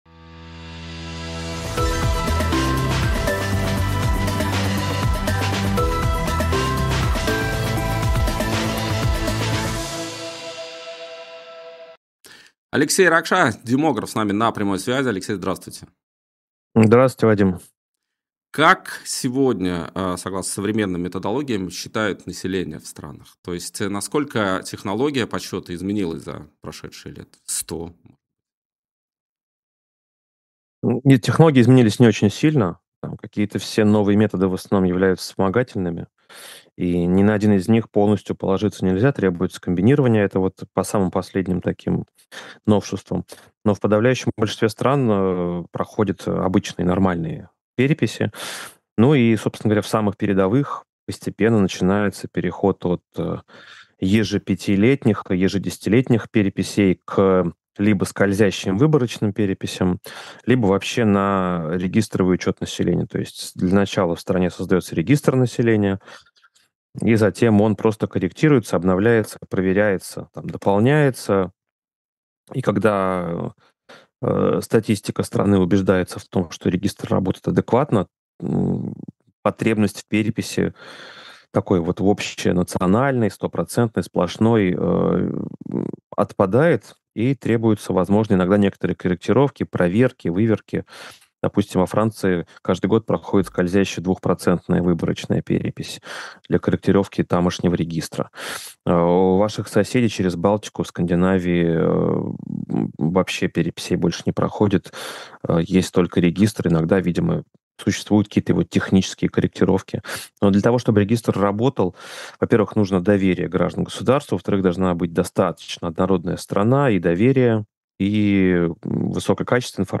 демограф